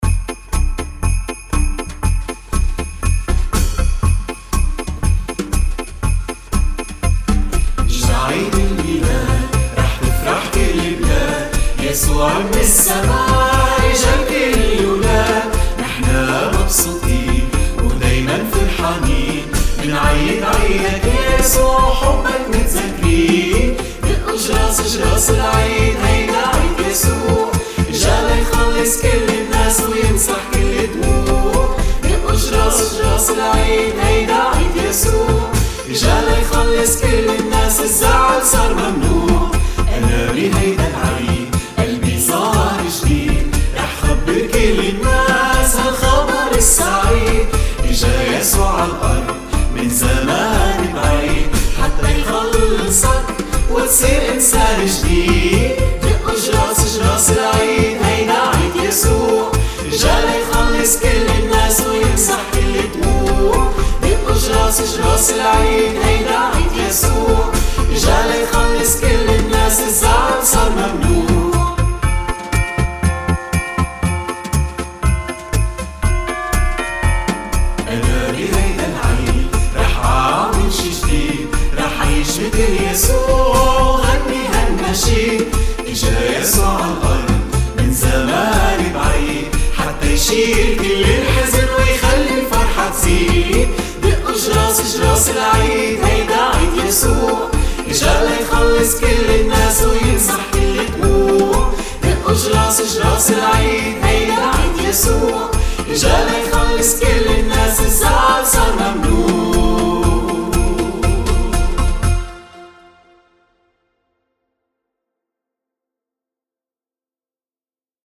F Major
125 BPM